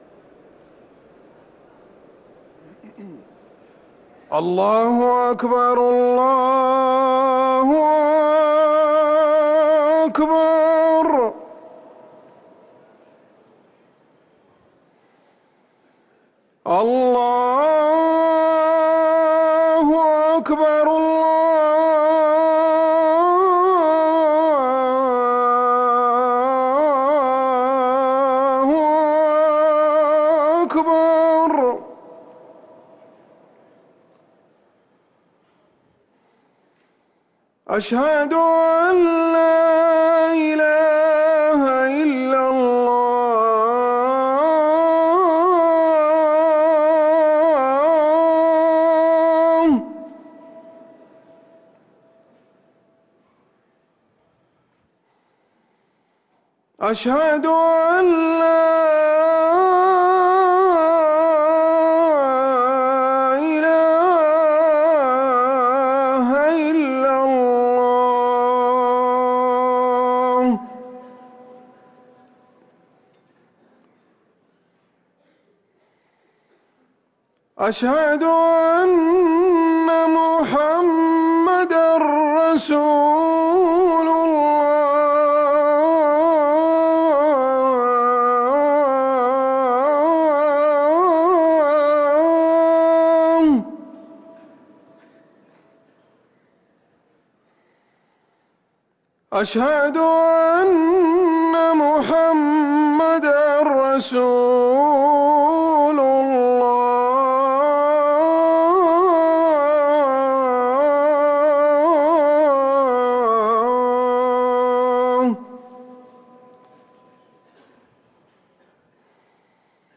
أذان العصر للمؤذن عمر سنبل الخميس 21 شوال 1444هـ > ١٤٤٤ 🕌 > ركن الأذان 🕌 > المزيد - تلاوات الحرمين